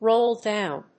róll dówn